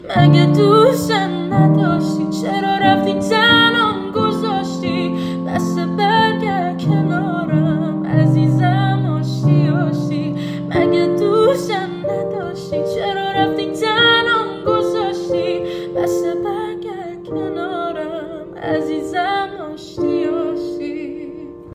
صدای دختر